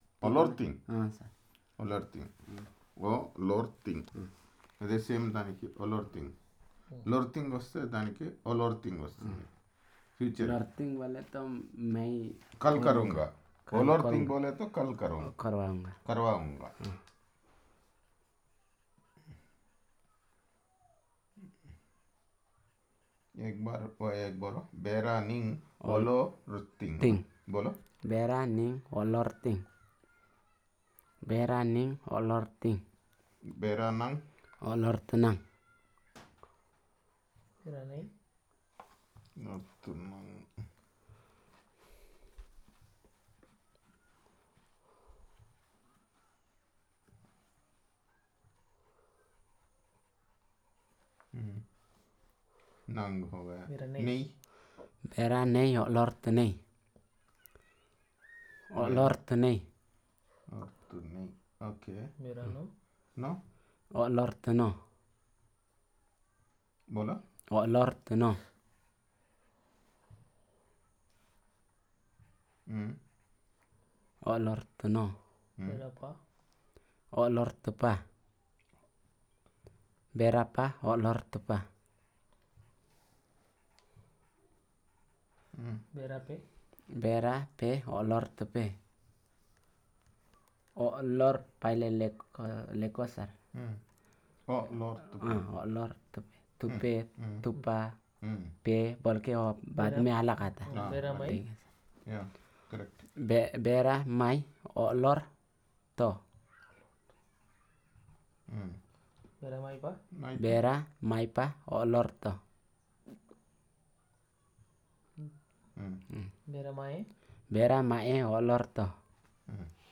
Elicitation of words on Elicitation of verbs in the continuous tense